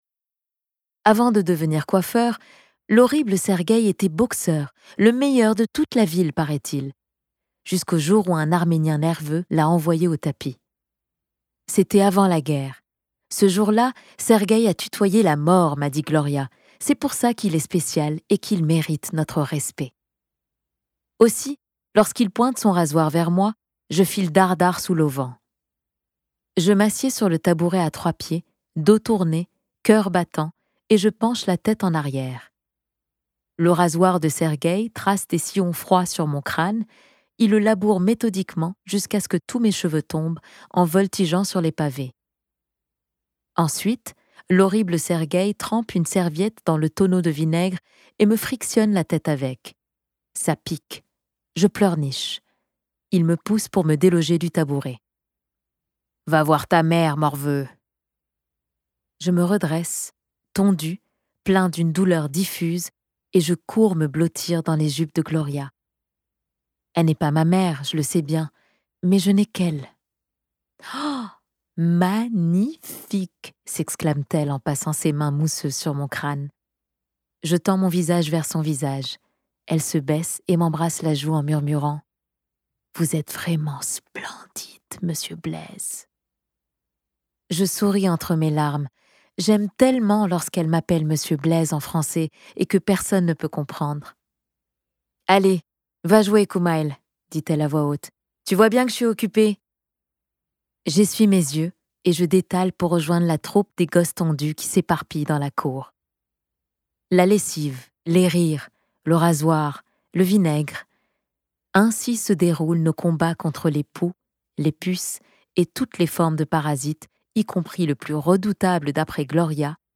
Audiobook DM